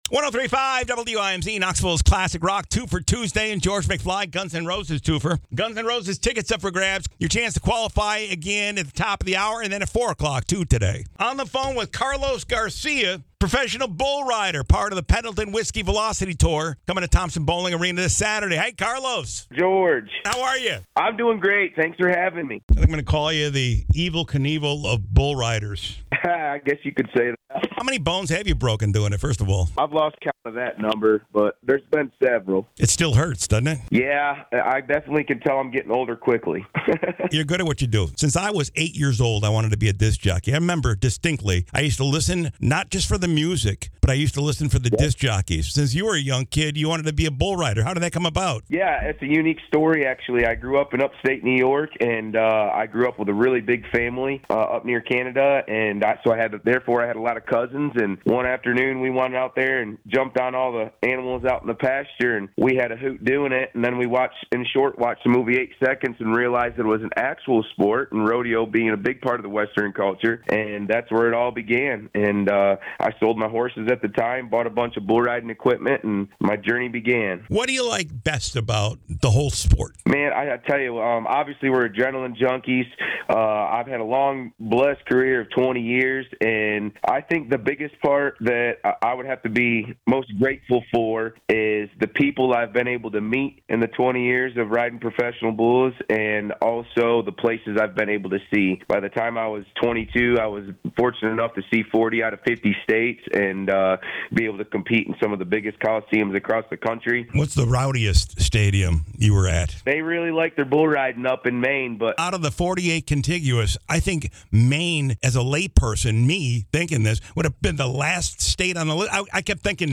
called my show today on 103.5 WIMZ!